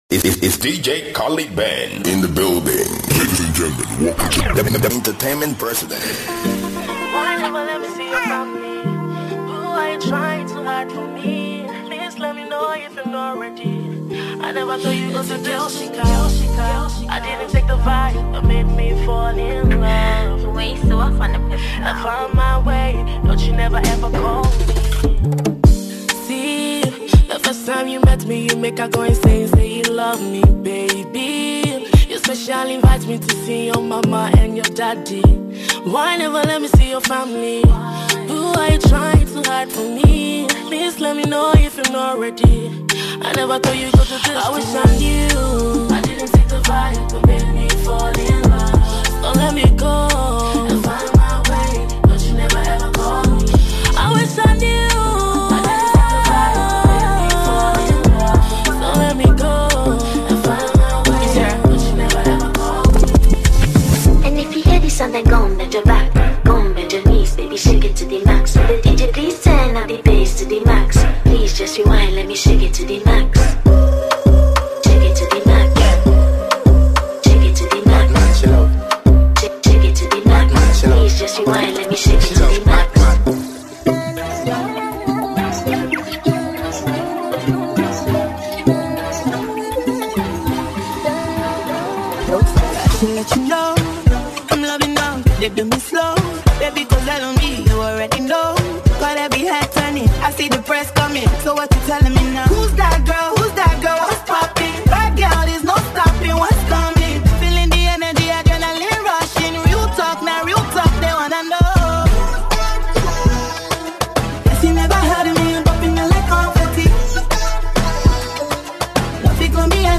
mixtape